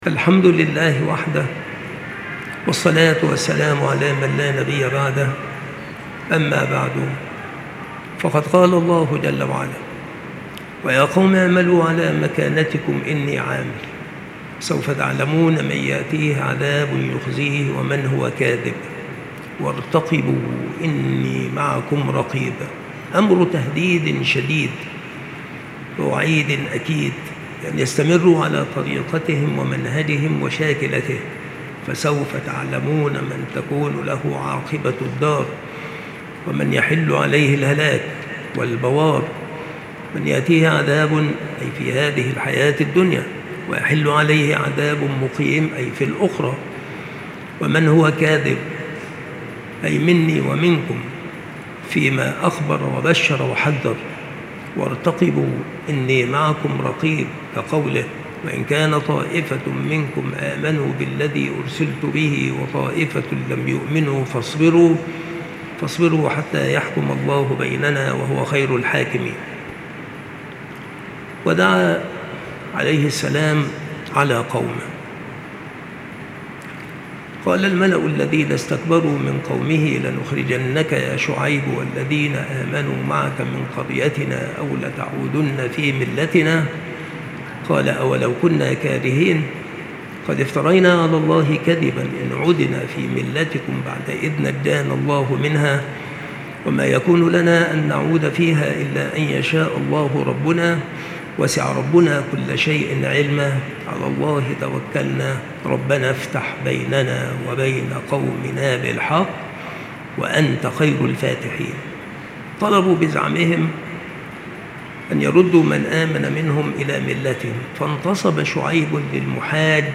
التصنيف قصص الأنبياء
مكان إلقاء هذه المحاضرة بالمسجد الشرقي - سبك الأحد - أشمون - محافظة المنوفية - مصر